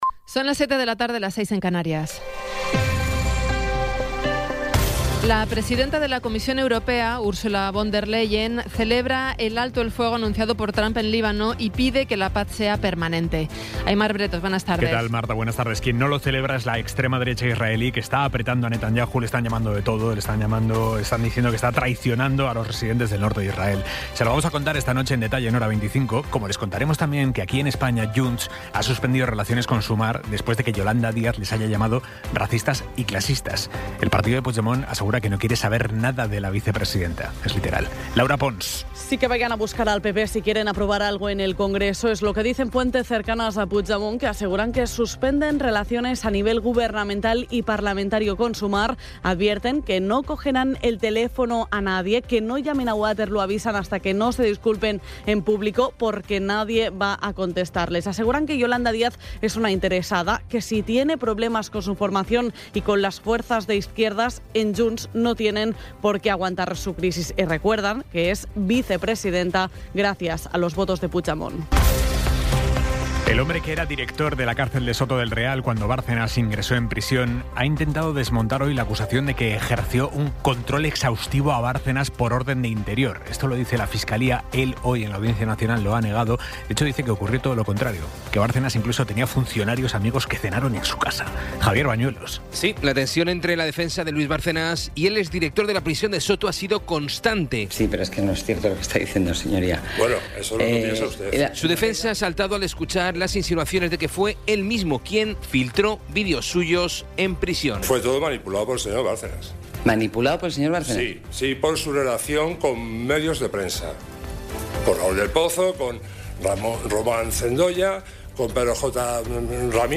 Resumen informativo con las noticias más destacadas del 16 de abril de 2026 a las siete de la tarde.